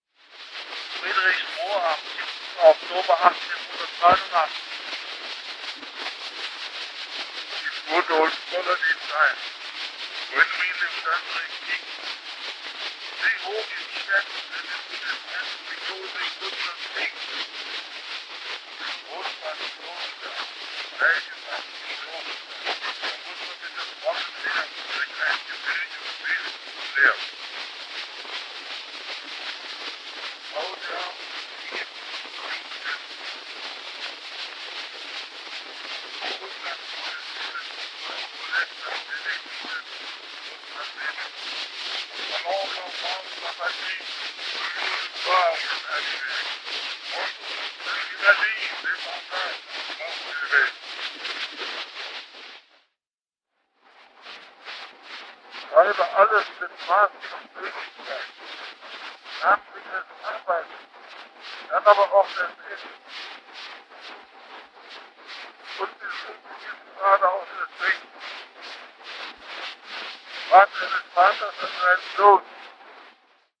Sprachaufnahme des Reichskanzlers Otto von Bismarck auf einer Edisonwalze in Friedrichsruh am 7. Oktober 1889. Sehr schlechte Tonqualität.
Sprachaufnahme_-_Otto_von_Bismarck_-_Edisonwalze_-_1889.mp3